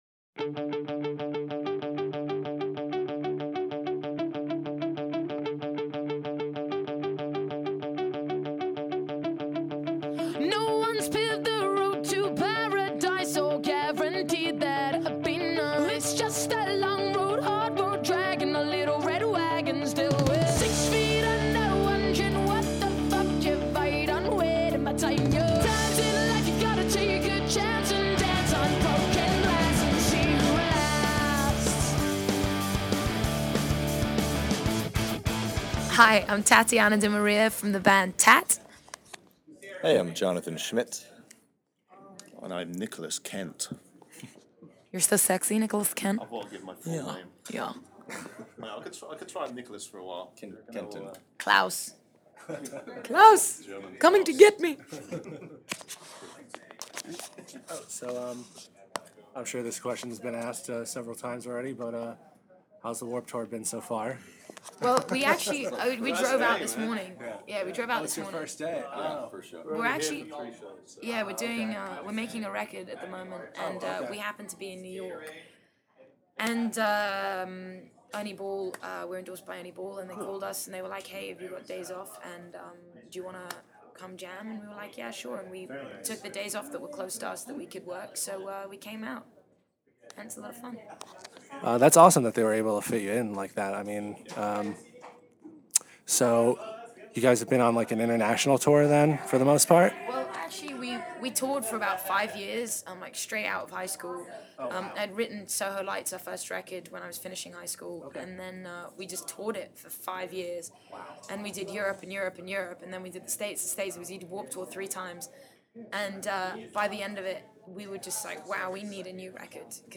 06-interview-tat.mp3